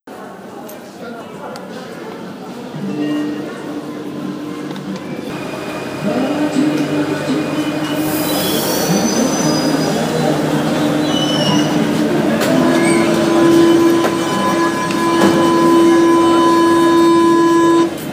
meteo-place-de-clichy.wav